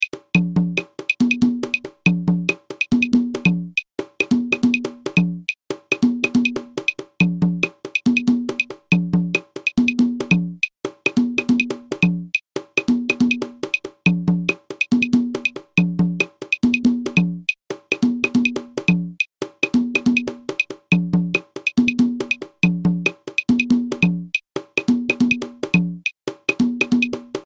• un cajón qui maintient la base rythmique ;
Guarapachangueo (cajón variation)
Partie de cajón avec clave 3/2
guarapachangueo_cajon6_clave32.mp3